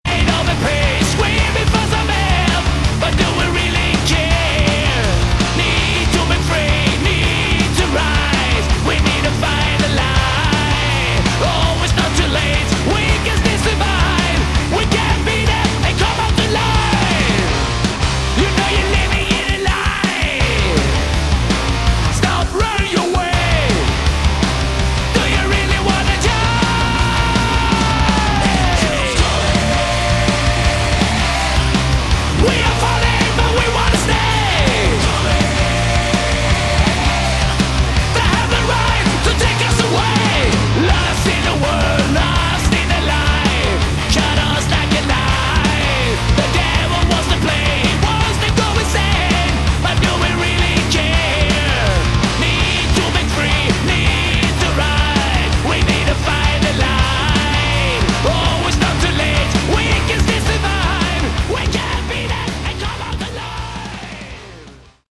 Category: Hard Rock
lead vocals, rhythm guitar
bass, backing vocals
drums, backing vocals
lead guitar, backing vocals